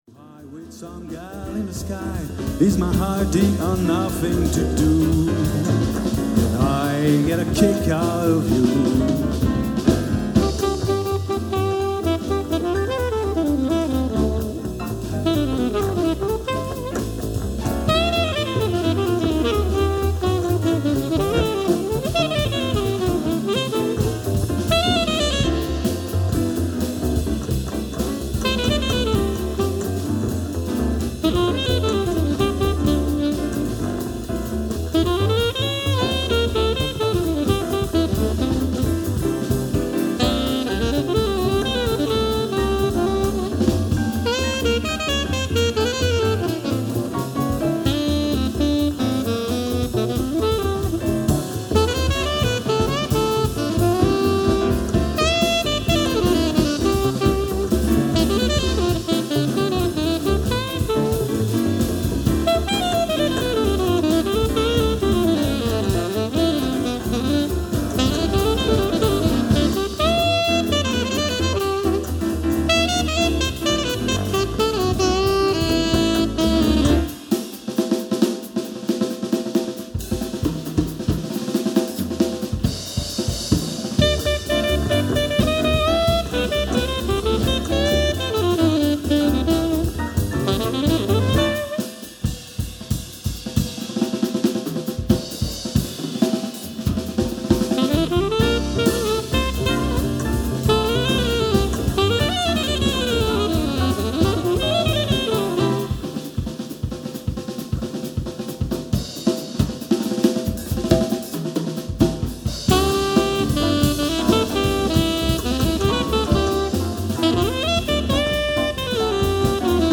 tout est fait dans la 01V96 sans périphes externe et sans aucune compression sur les sources (le piano était un truc électrique bon marché; j'ai fait ce que j'ai pu mais le son n'est pas terrible); la batteuse est prise avec 3 mics; la Co-Ba avec capteur Schertler est pris direct sur l'ampli pas avec un mic (le musicien le voulait ainsi)
puis à la maison j'ai fait un petit mastering mais en restant raisonnable sur le Loudness, juste pour relever un peu le relief et le volume et ça donne :
jazz_norm.mp3